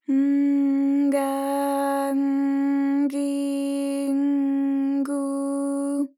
ALYS-DB-001-JPN - First Japanese UTAU vocal library of ALYS.
g_N_ga_N_gi_N_gu.wav